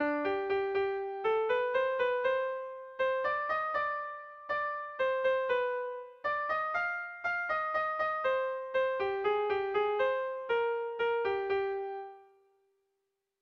Irrizkoa
ABD